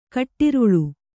♪ kaṭṭiruḷu